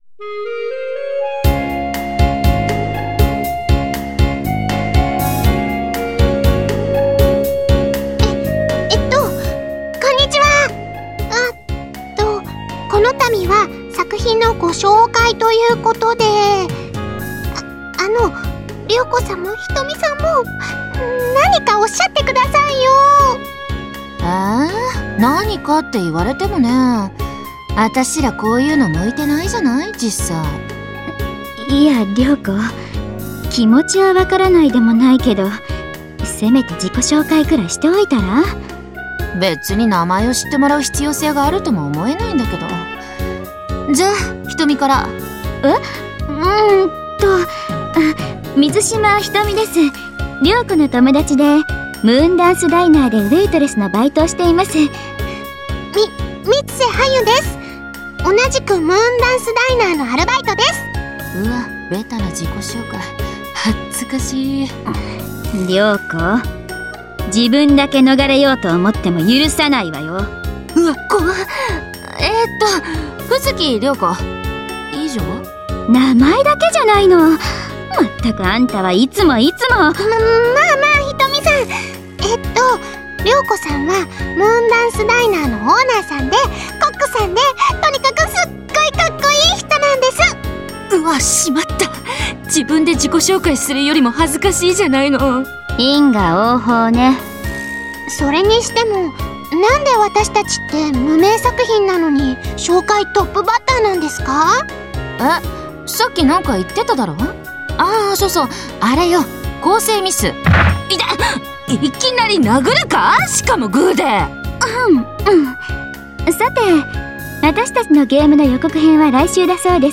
ＶＯＩＣＥ ＴＲＡＩＬＥＲ　第一弾　　『シャマナシャマナ 予告編』　　７．９５ＭＢ